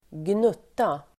Uttal: [²gn'ut:a]